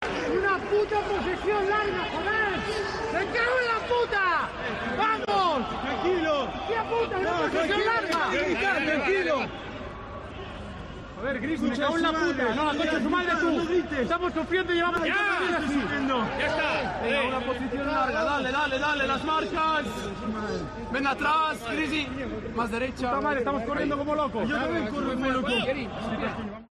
AUDIO: Con el 1-1 en el marcador, el central azulgrana explotó ante la falta de intensidad de sus compañeros antes de un lanzamiento de esquina.